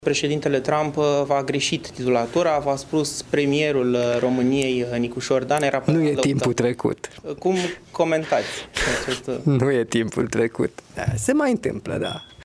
Nicușor Dan a răspuns pe ton de glumă când a fost întrebat de jurnaliști despre gafa lui Donald Trump.